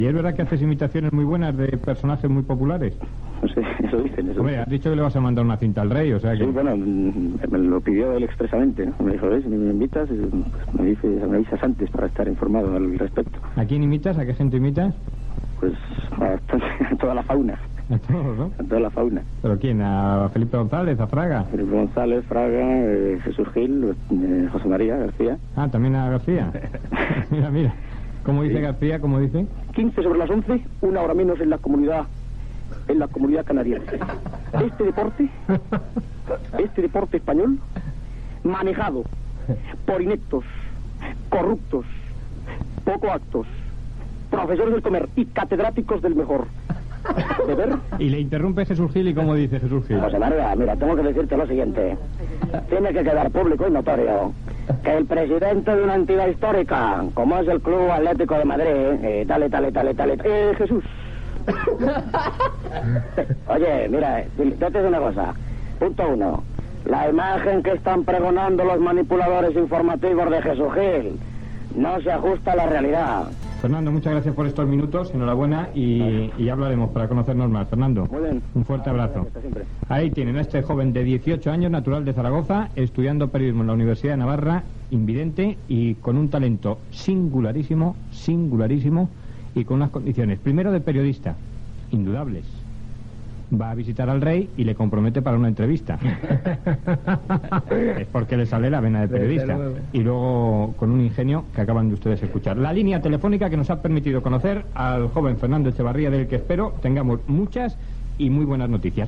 Fragment d'una entrevista
que fa imitacions del periodista José María García i del president del club Atlético de Madrid, Jesús Gil
Info-entreteniment